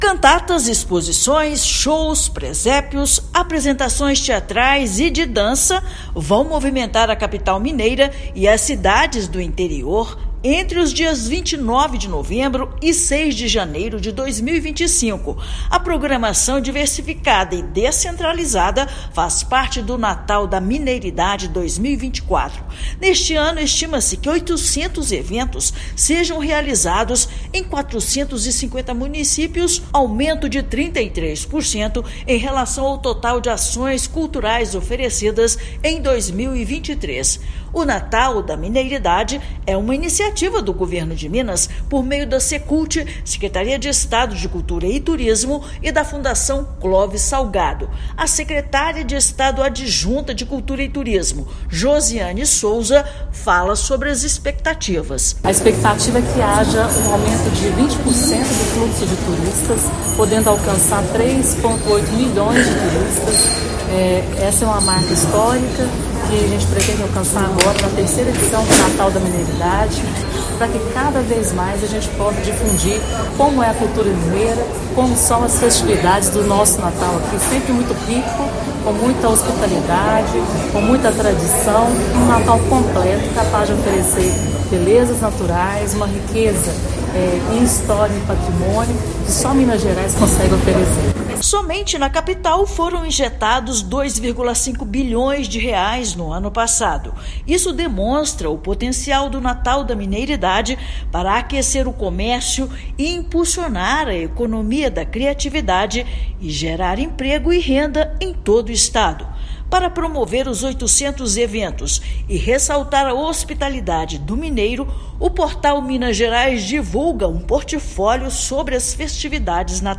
Do barroco ao modernismo, história, fé e tradição se unem no estado síntese da cultura brasileira para celebrar o Natal da Mineiridade. Ouça matéria de rádio.